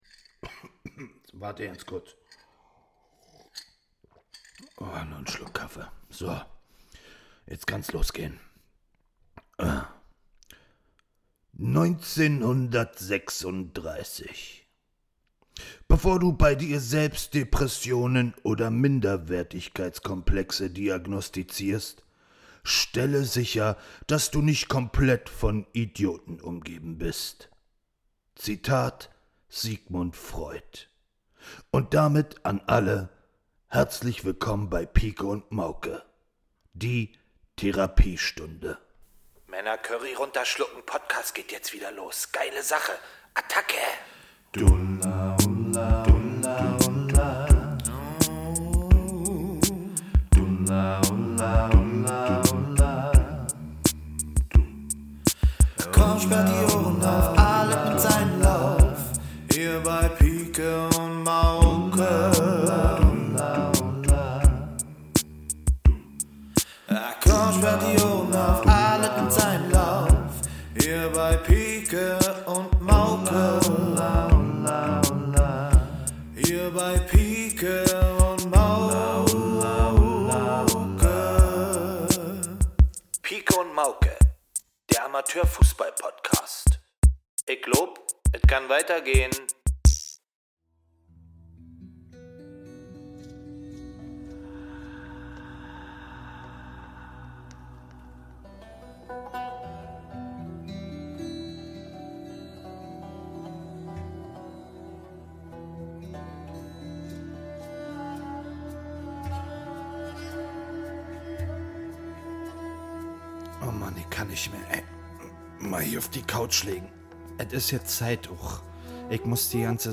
Es wird tief gegraben, viel gesprochen und die Seele frei gemacht. 60 Minuten, die ein einziger Monolog sind inklusive der Erkenntnis, dass Schizophrenie bei einem der Berliner Originale vielleicht Einzug erhält.